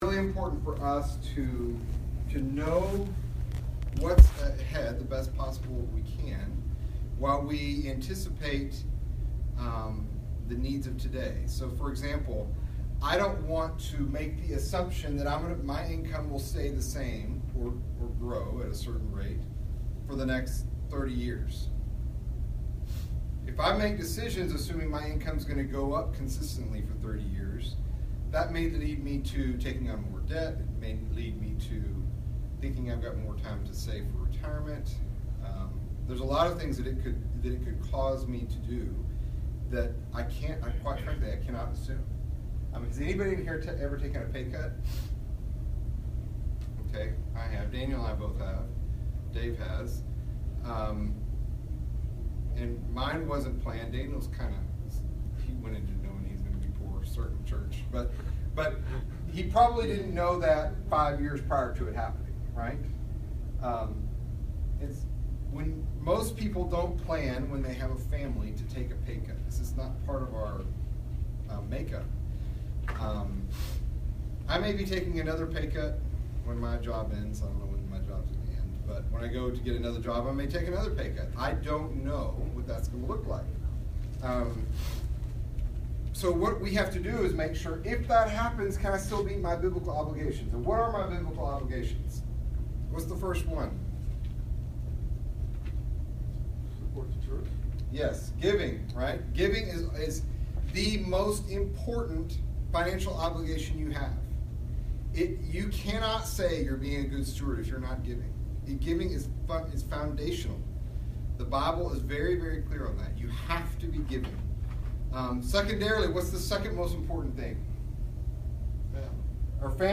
Download Files MP3 Sermon Topics: Debt ERROR: The IP key is no longer supported.